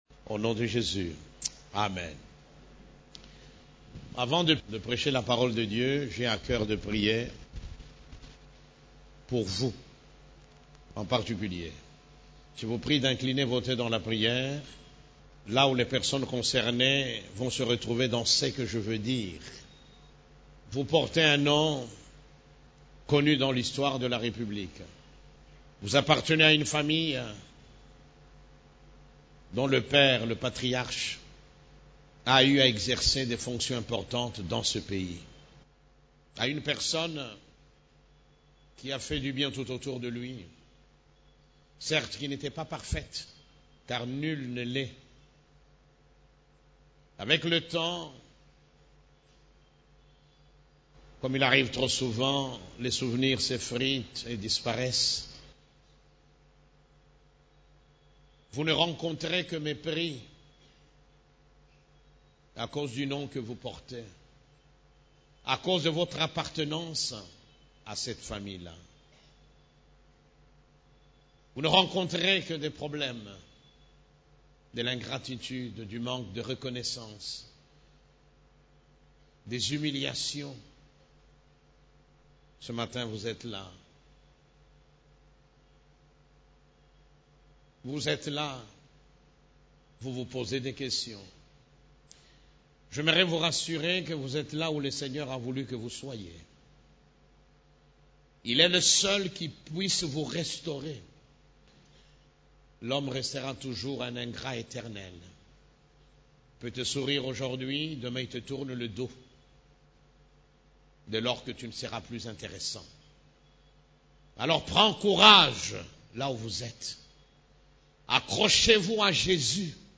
CEF la Borne, Culte du Dimanche, Créés et rachetés pour régner dans cette vie (3)